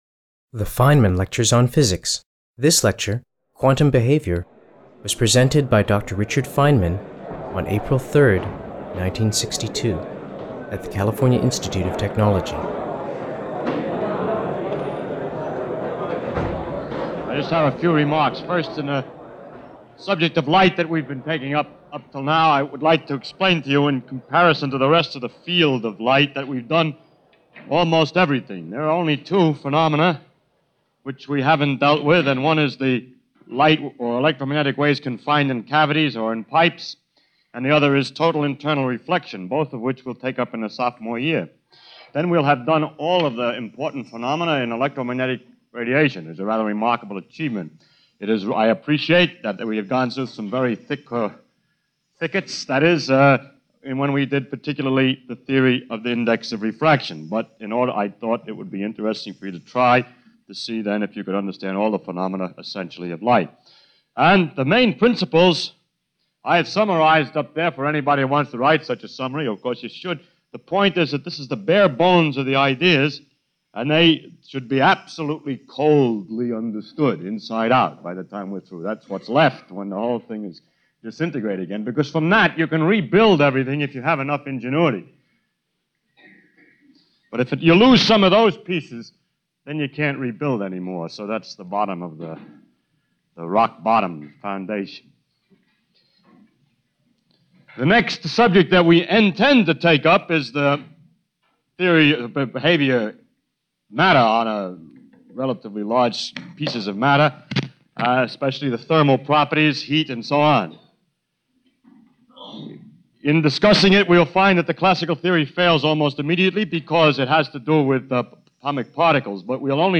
Feynman lecture quantum.wma